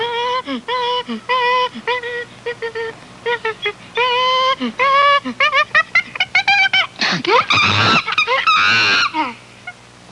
Chimp Screeching Sound Effect
Download a high-quality chimp screeching sound effect.
chimp-screeching.mp3